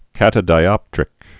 (kătə-dī-ŏptrĭk)